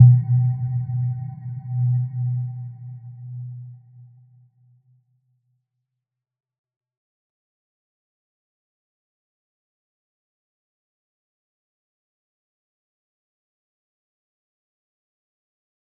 Little-Pluck-B2-mf.wav